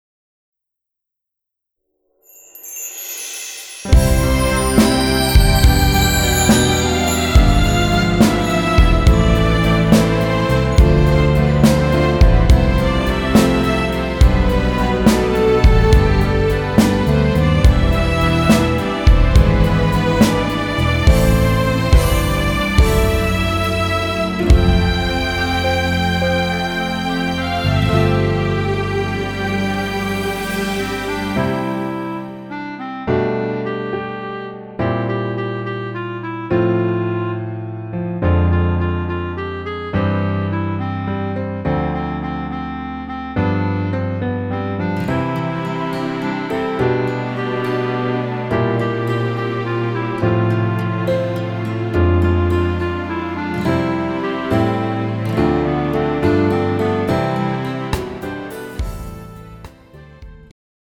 음정 -2키
장르 축가 구분 Pro MR